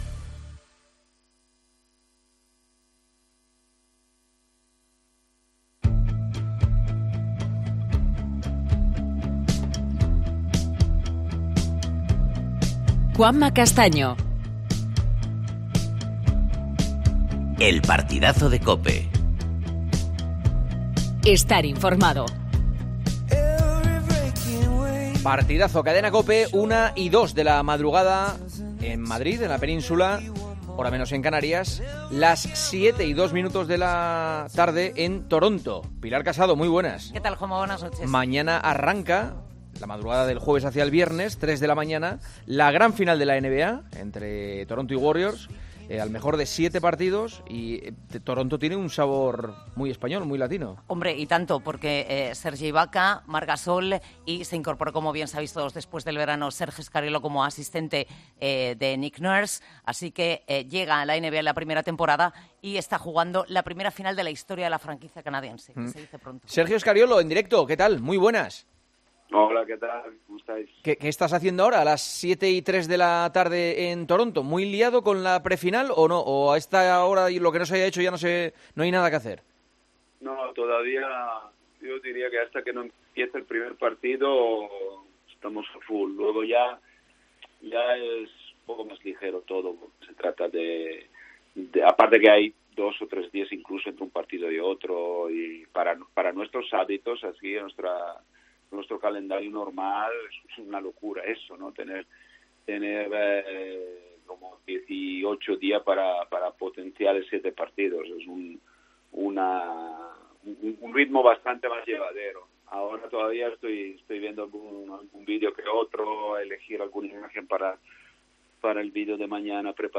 "El Partidazo de COPE" entrevista al entrenador asistente de Toronto Raptors, que juega la final de la NBA ante los Warriors